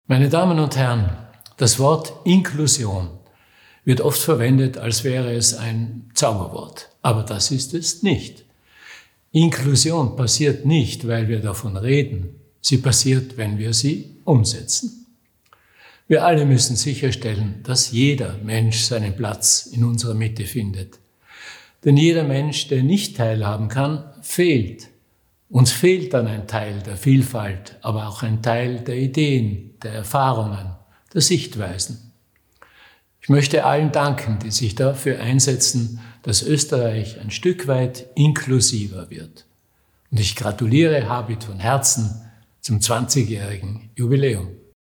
Bewegende Botschaften zum Thema Inklusion, gesprochen von Menschen aus Kunst, Kultur, Politik und Wissenschaft.
Bundespräsident